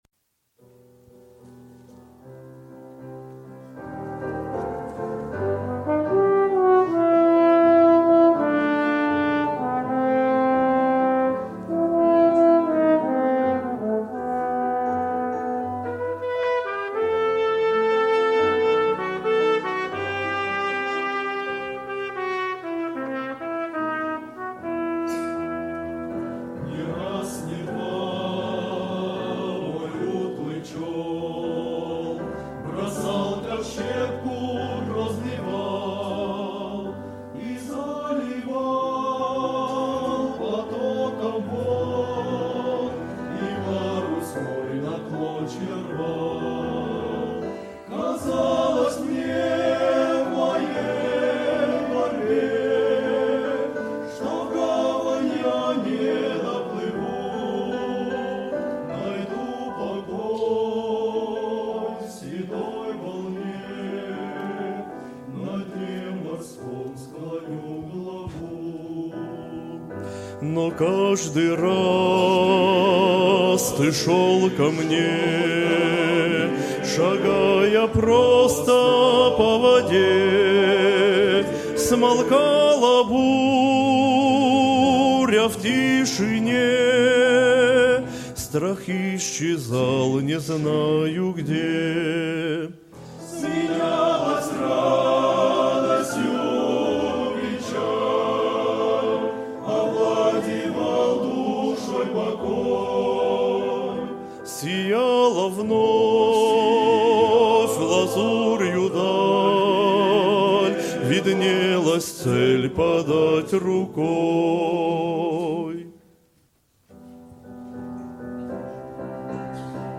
91 просмотр 92 прослушивания 6 скачиваний BPM: 77